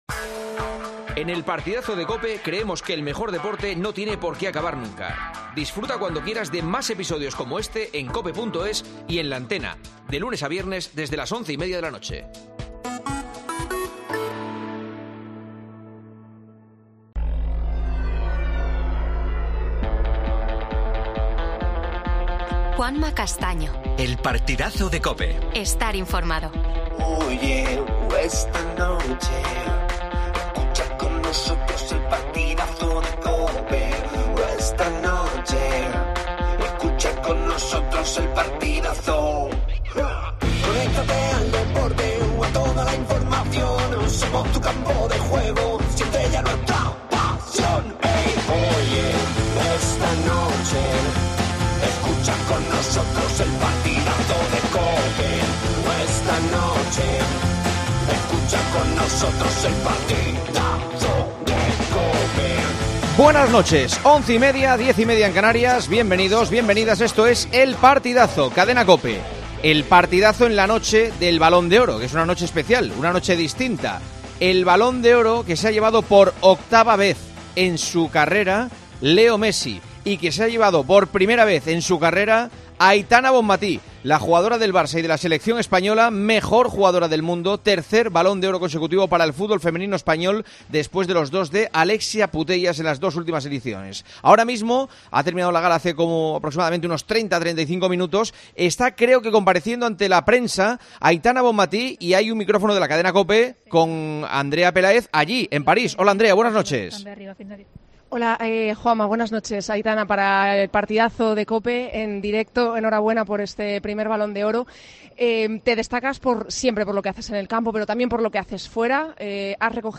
AUDIO: Noticias del día. Leo Messi y Aitana Bonmatí, Balones de Oro 2023. Escuchamos a los protagonistas del día.